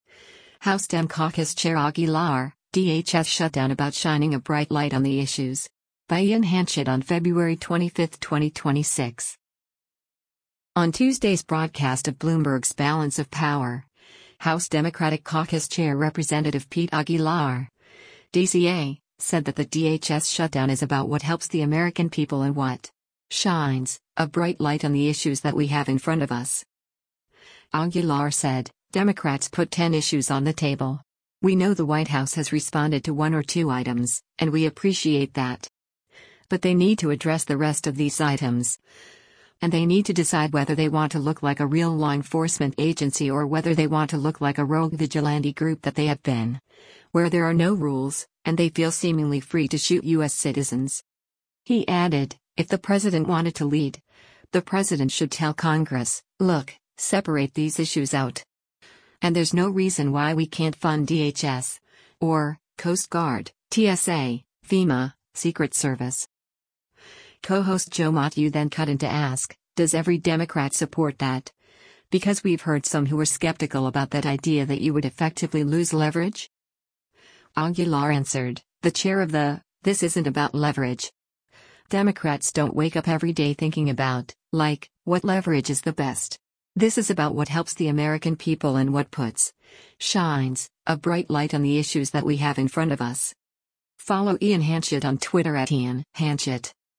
On Tuesday’s broadcast of Bloomberg’s “Balance of Power,” House Democratic Caucus Chair Rep. Pete Aguilar (D-CA) said that the DHS shutdown “is about what helps the American people and what…[shines] a bright light on the issues that we have in front of us.”